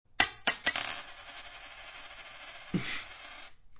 Index of /html/coin_sounds/